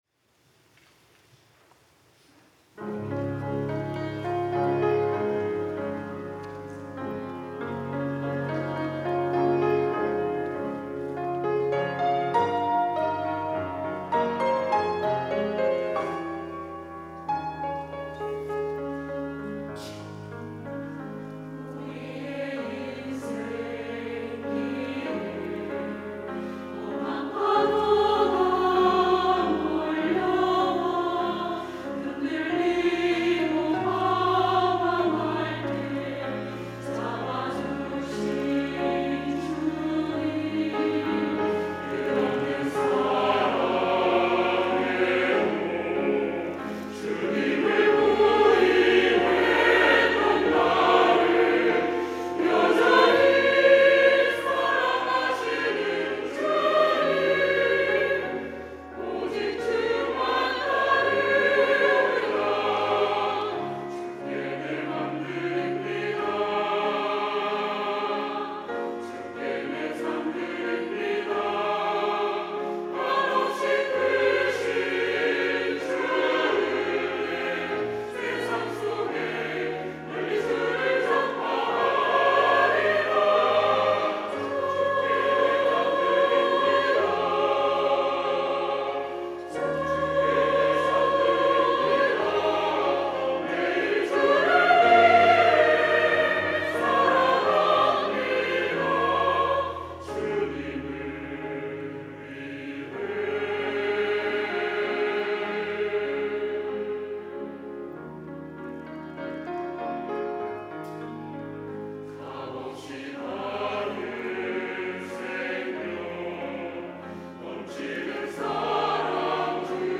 호산나(주일3부) - 주님을 위해
찬양대